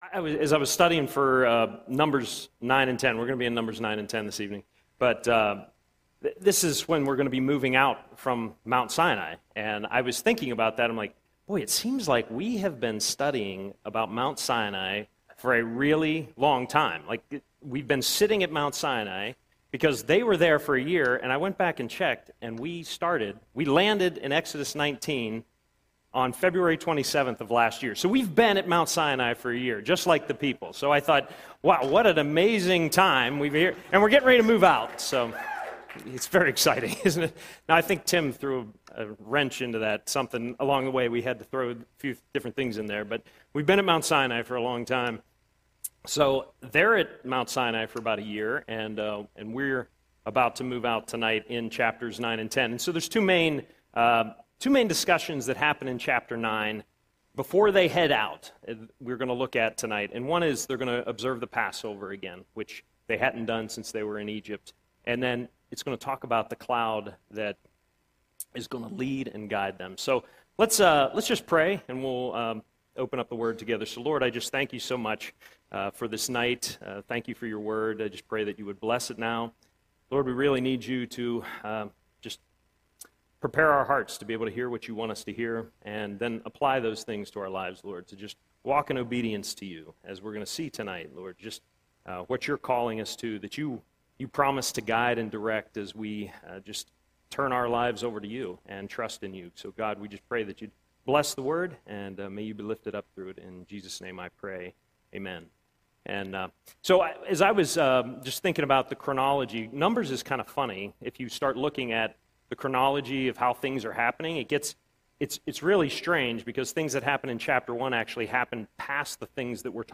Audio Sermon - March 4, 2026